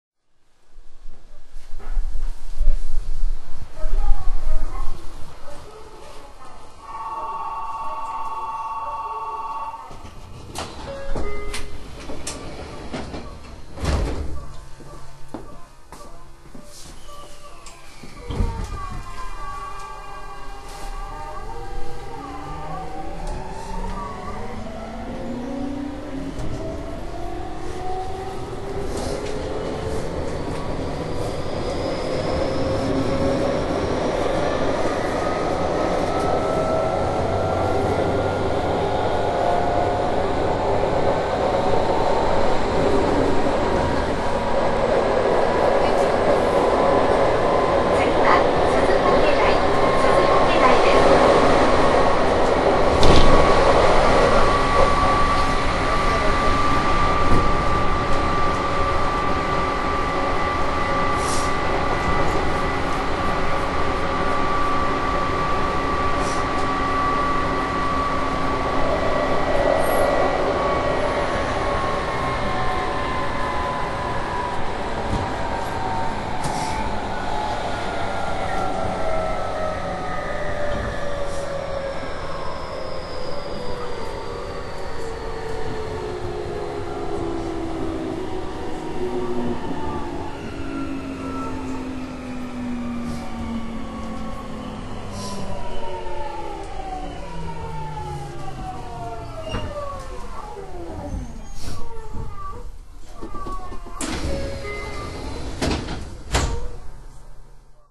田園都市線で活躍する車輌で、日立製作所製の後期GTO-VVVFインバータを装備します。
音は京王8000系や西武6000系と似ているが、一本調子の音のあと音の下がりが少なく
上がる点が異なっており、東京メトロ9000系に似た感じと言えそうです。
↓のファイルでは、珍しく停止寸前に東急9000系のように非同期音が一瞬鳴ります＾＾；
南町田〜すずかけ台 （８６１KB）